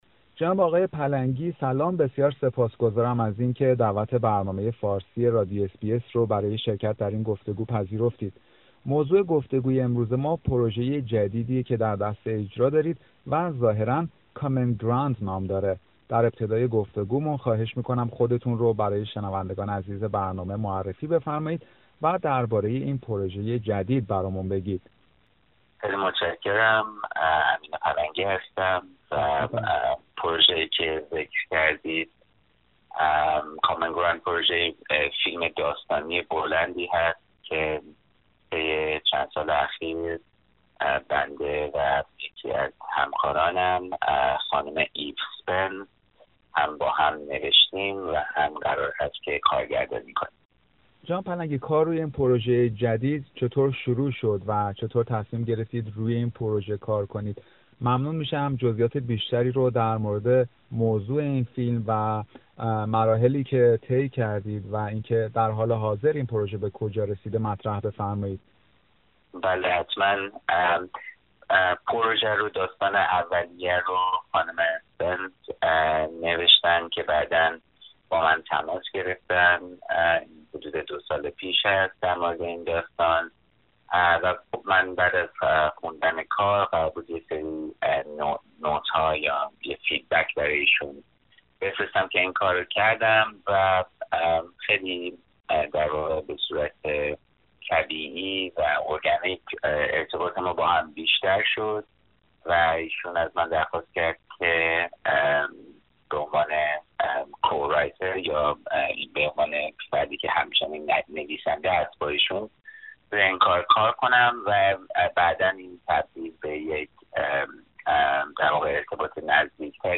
برنامه فارسی رادیو اس بی اس گفتگویی داشته است